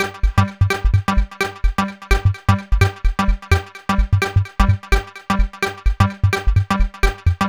VFH1 128BPM Moonpatrol Melody 1.wav